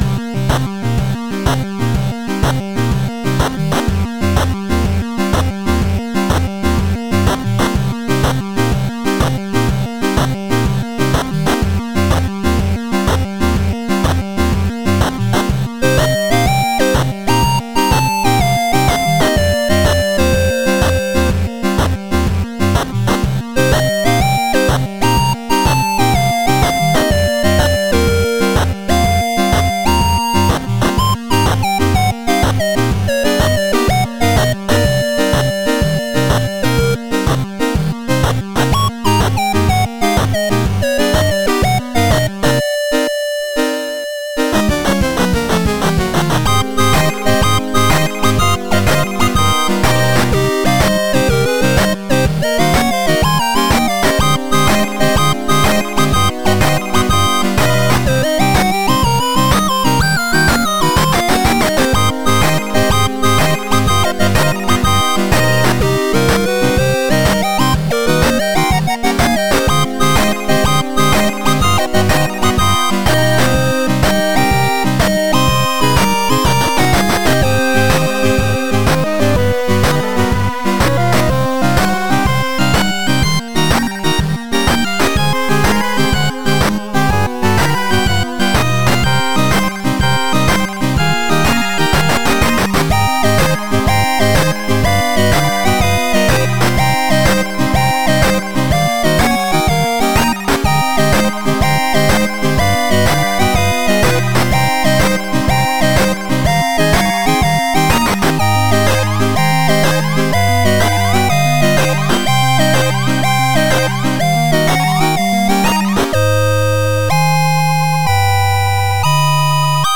BPM124
Audio QualityPerfect (High Quality)
Comments[TWELVE-BAR CHIPTUNE]